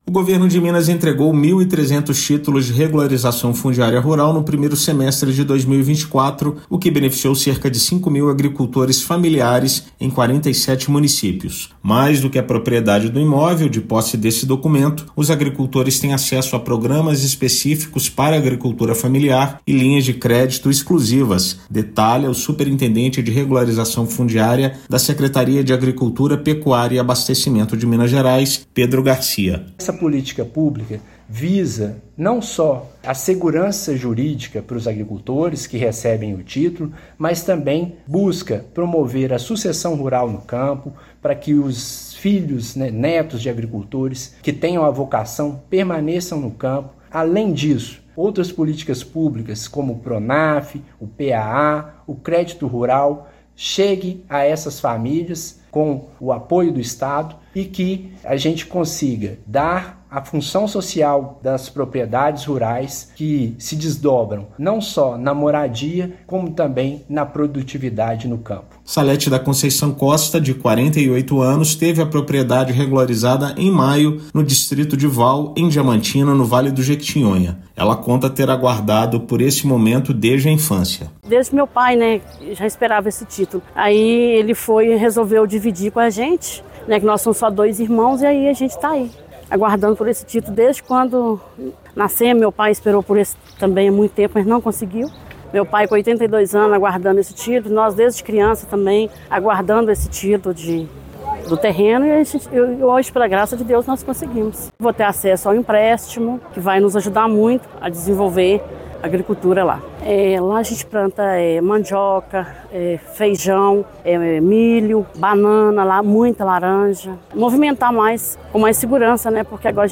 Cerca de 5 mil agricultores familiares de 47 municípios mineiros foram beneficiados pela política pública no período. Ouça matéria de rádio.